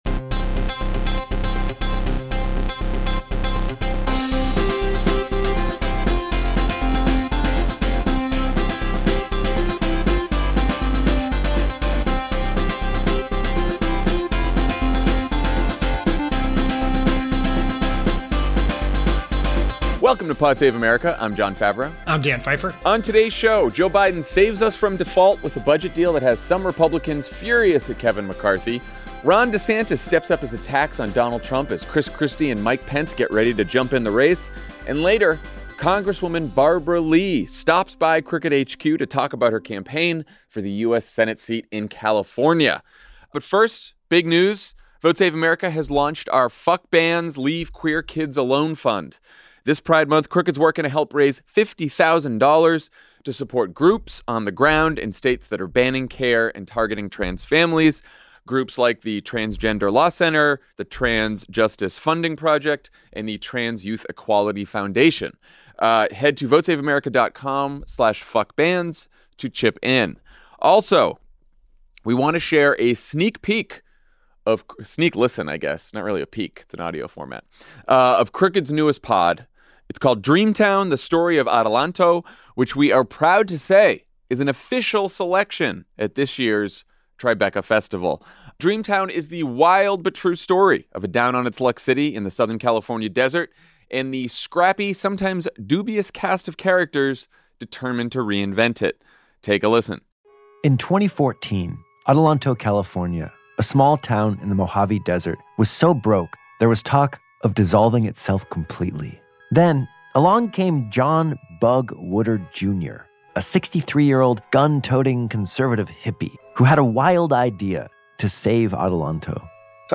Joe Biden saves the country from default with a budget deal that has some Republicans furious at Kevin McCarthy. Ron DeSantis steps up his attacks on Donald Trump as Chris Christie and Mike Pence get ready to jump in the race. And later, Congresswoman Barbara Lee stops by to talk about her campaign for the Senate seat in California.